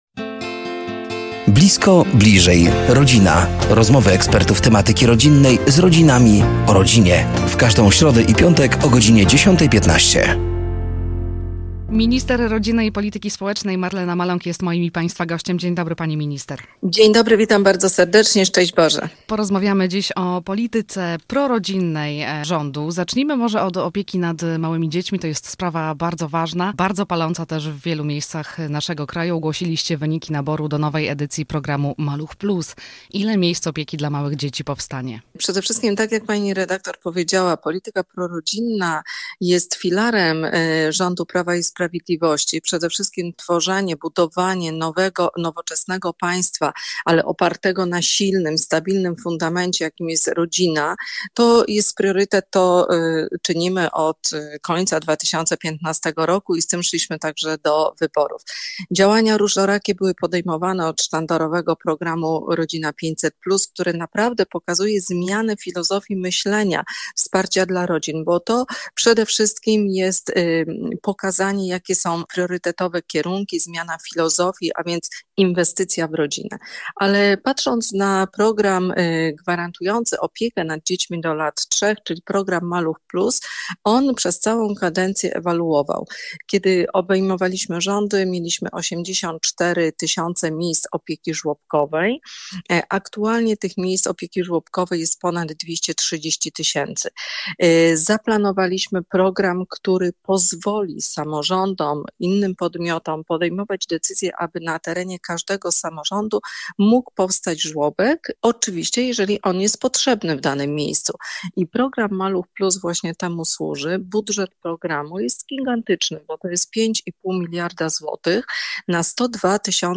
Do studia zaproszeni są eksperci w temacie rodziny i rodzicielstwa.
Gościem pierwszej audycji była Minister Rodziny i Polityki Społecznej, Marlena Maląg.